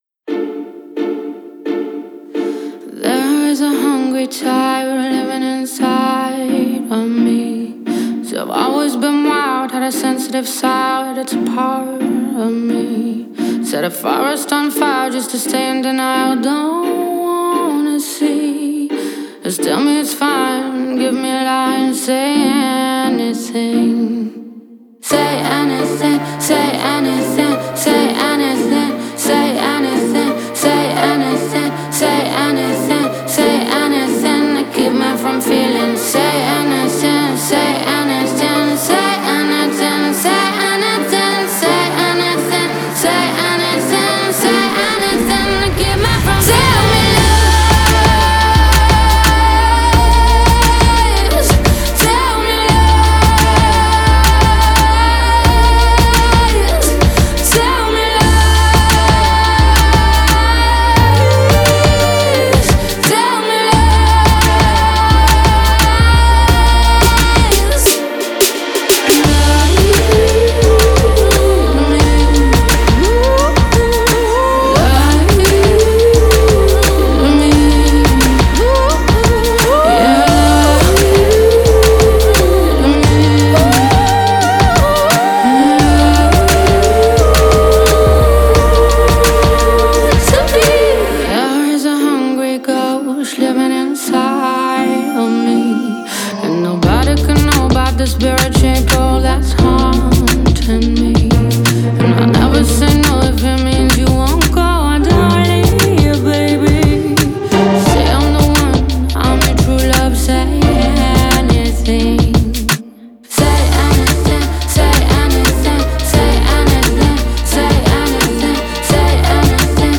эмоциональная поп-песня